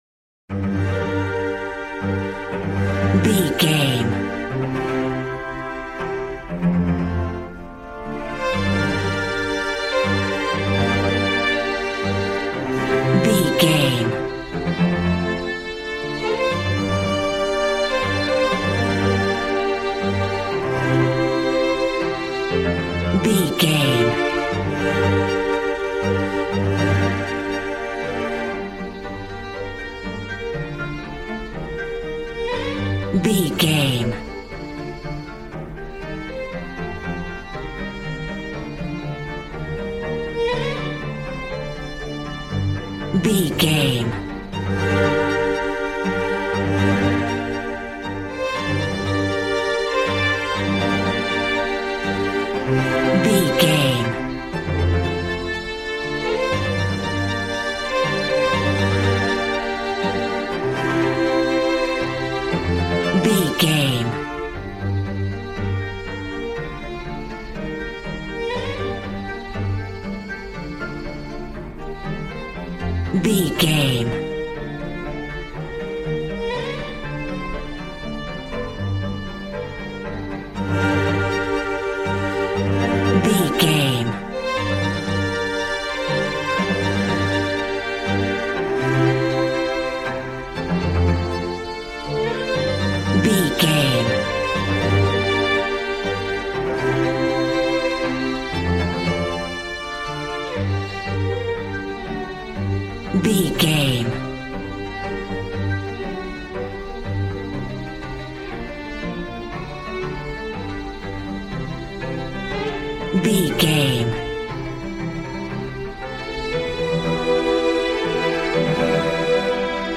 Modern film strings for romantic love themes.
Regal and romantic, a classy piece of classical music.
Aeolian/Minor
regal
cello
violin
brass